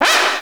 Cri de Gruikui dans Pokémon Noir et Blanc.